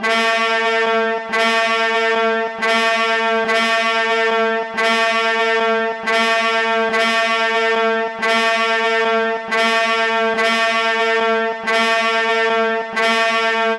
Exodus - Horn Siren.wav